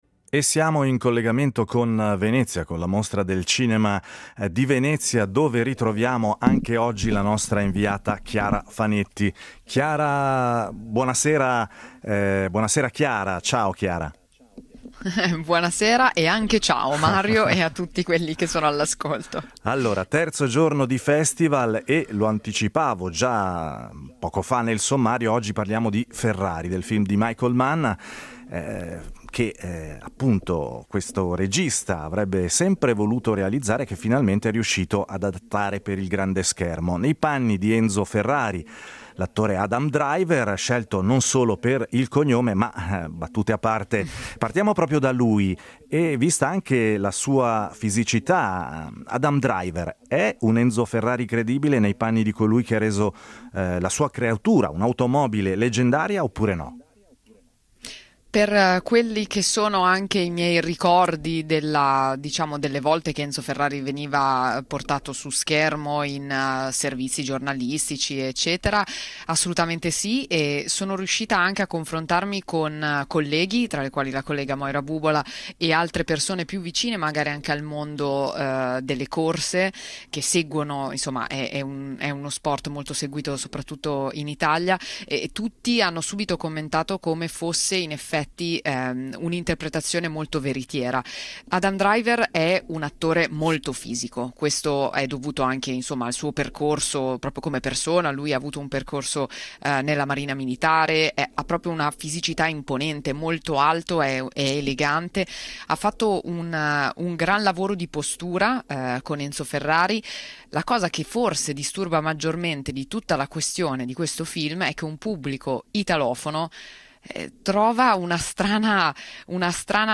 Interviste, anticipazioni, notizie, recensioni e retroscena dall’ottantesima edizione della Mostra internazionale d’arte cinematografica di Venezia .